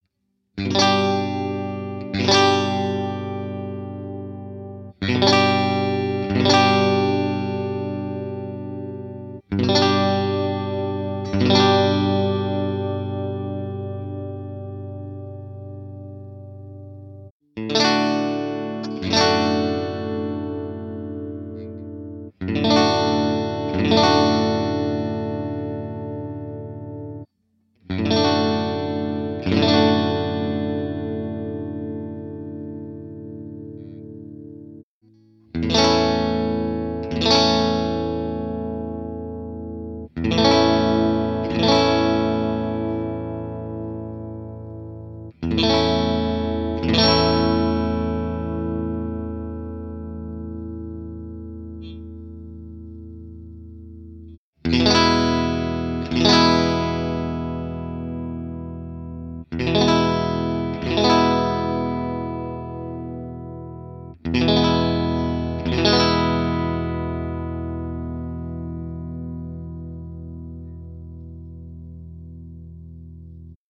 These are pretty boring examples, I did just strumm some chords. Each chord is strummed twice, then the guitar changes. All samples are done with the same cable and for sure its the same amp, because I recorded without amp with a Line6 Pod Xt and the simulation is done on the PC by the Line6 Soundfarm plugin.
Neck-middle
So, always first is the Squier CV, then the G&L Legacy and at last the Hohner with the Fender Texas Specials.